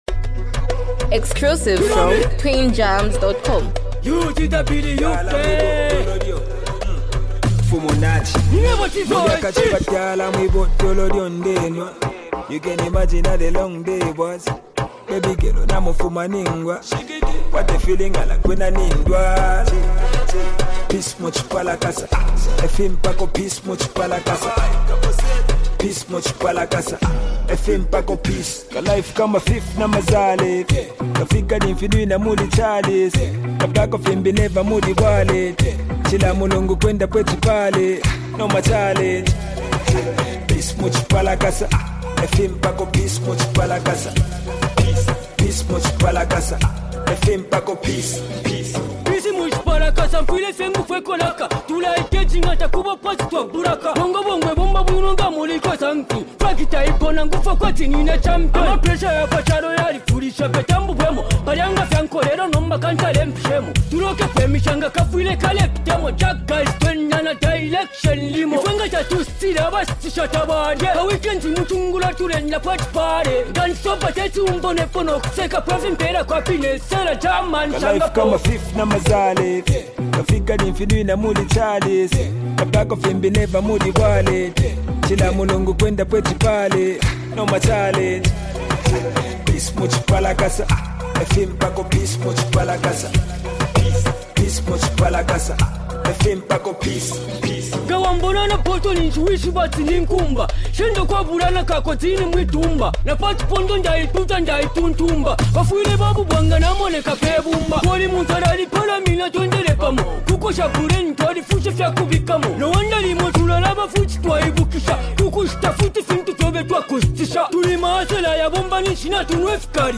hype, street vibes, and a powerful hook with unique rap flow
multi talented rapper